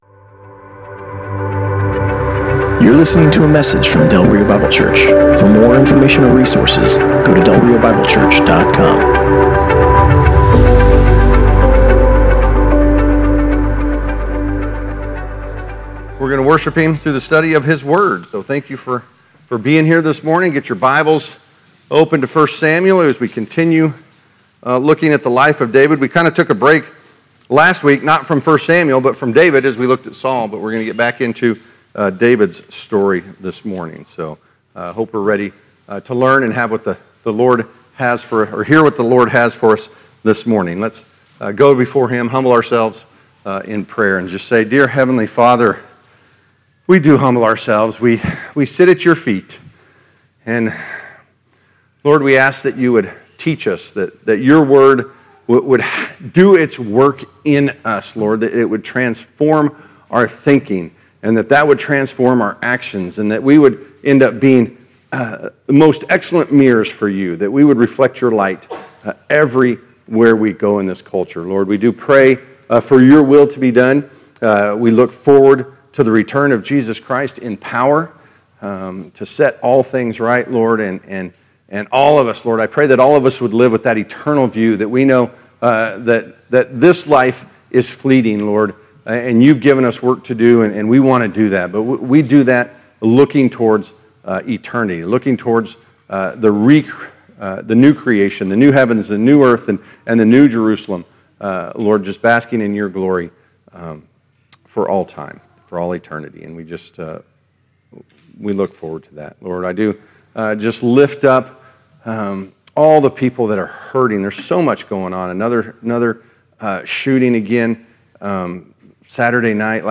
Passage: 1 Samuel 29:1-30:31 Service Type: Sunday Morning